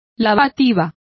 Complete with pronunciation of the translation of enema.